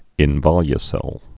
(ĭn-vŏlyə-sĕl)